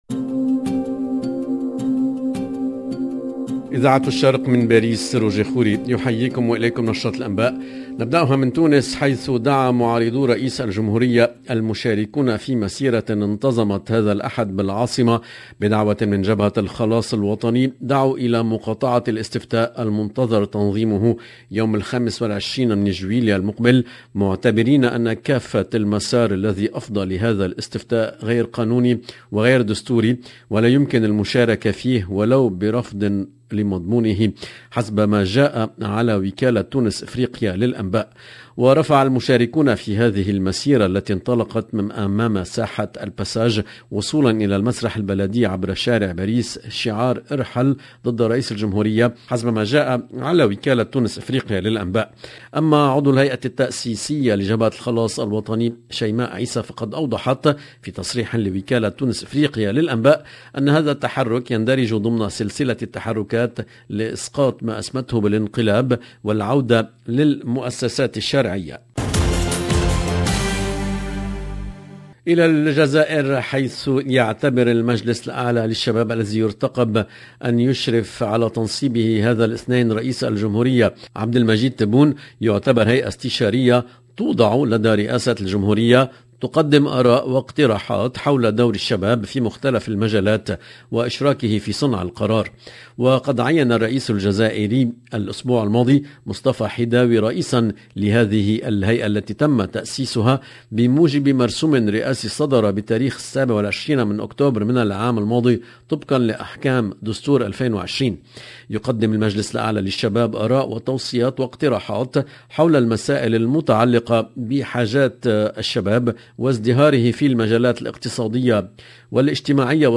LE JOURNAL EN LANGUE ARABE DU 19/06/22